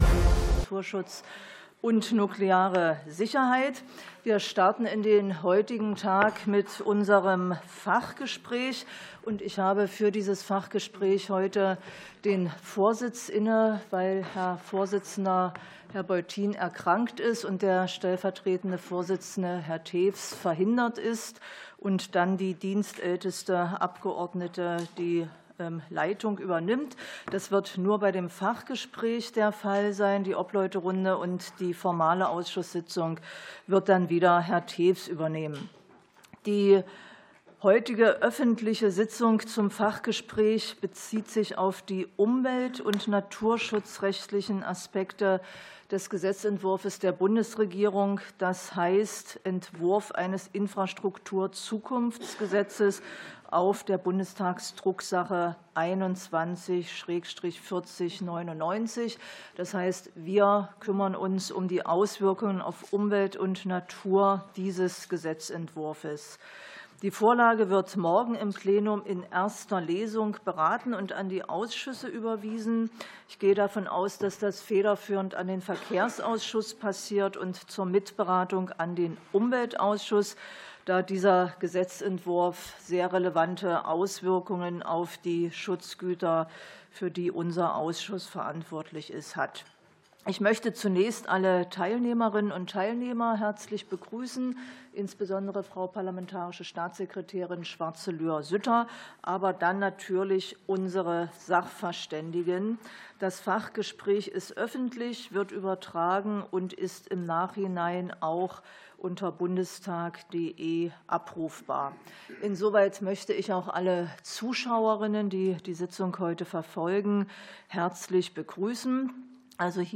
Umwelt- und Naturschutz im Infrastruktur-Zukunftsgesetz ~ Ausschusssitzungen - Audio Podcasts Podcast